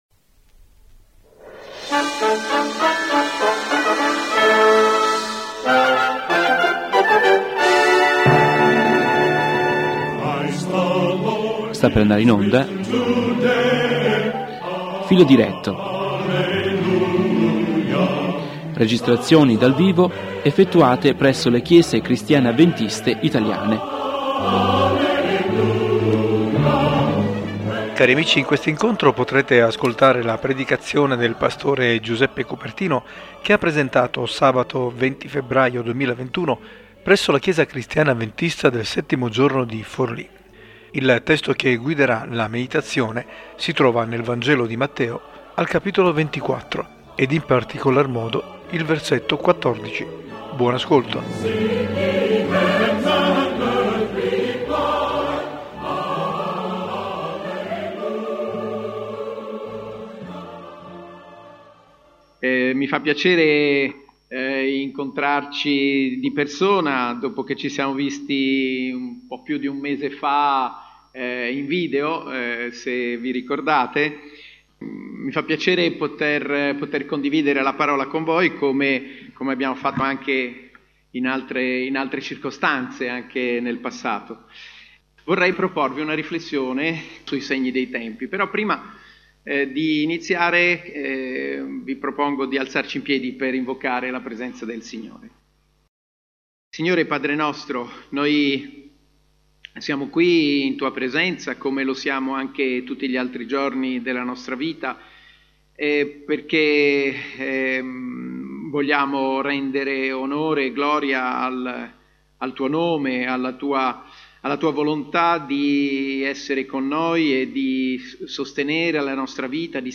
Filo Diretto FC 164: (Segni dei tempi) Registrazioni dal vivo dalle comunità avventiste in Italia. In questa puntata: Il capitolo 24 dell'Evangelo di Matteo, parla del sermone sul monte di Gesù, dove il messia rivolgendosi agli uditori parlerà della sorte del mondo e dei segni precursori della fine.
La predicazione è stata presentata sabato 20/02/2021 presso la chiesa cristiana avventista del settimo giorno di Forlì.